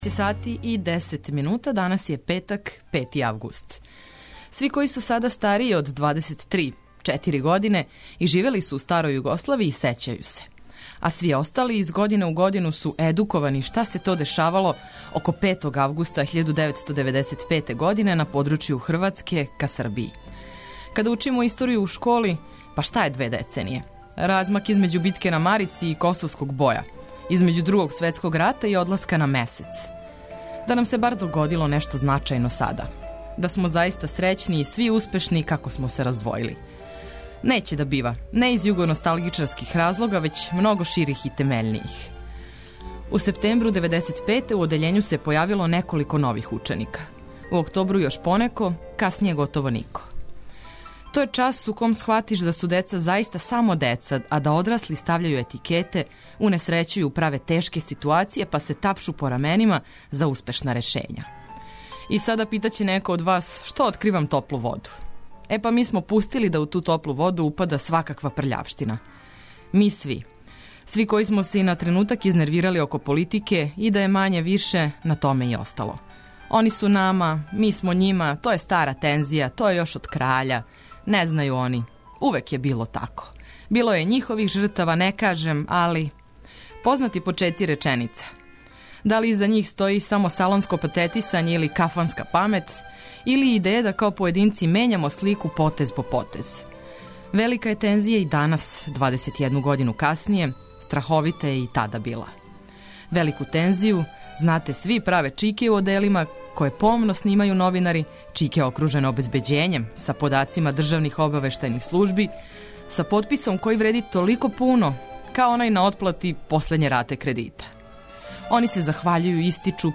Током целог јутра ту су сервисне информације, вести, прелиставање домаће штампе, информације о стању на путевима, а има и простора да ви кројите са нама Устанак!